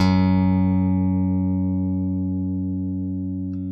ALEM PICKF#2.wav